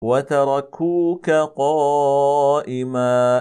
c ـــ Uttala det liknande [qāf — ق], som i: